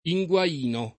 vai all'elenco alfabetico delle voci ingrandisci il carattere 100% rimpicciolisci il carattere stampa invia tramite posta elettronica codividi su Facebook inguainare v.; inguaino [ i jgU a & no ; non i jgU# - ] — cfr. guaina